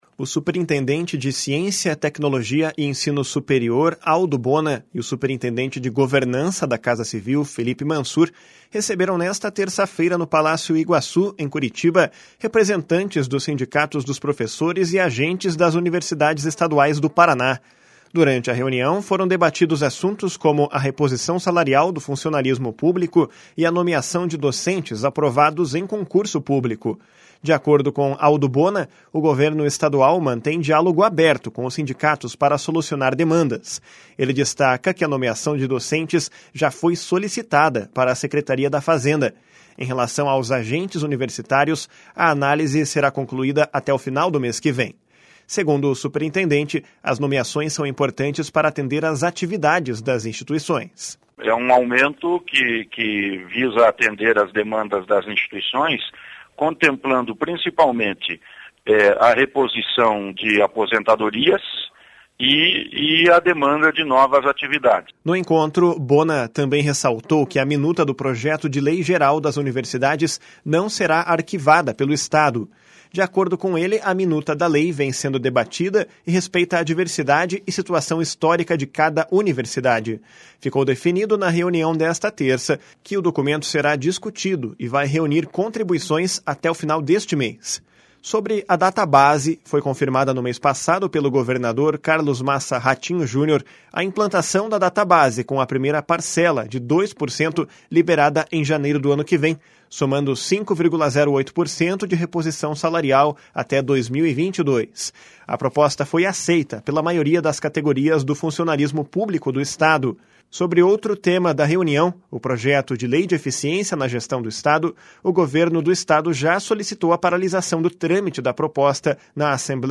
// SONORA ALDO BONA //